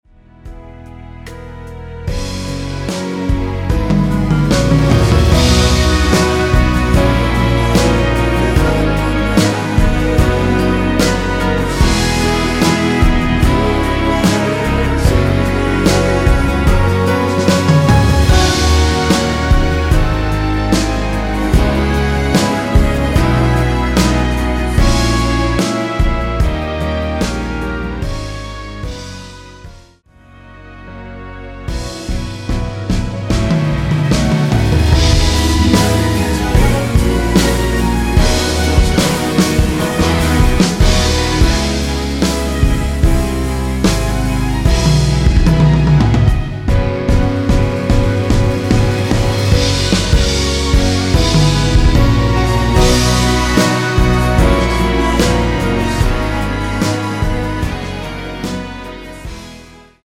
원키에서(-1)내린 코러스 포함된 MR입니다.(미리듣기 확인)
앞부분30초, 뒷부분30초씩 편집해서 올려 드리고 있습니다.
중간에 음이 끈어지고 다시 나오는 이유는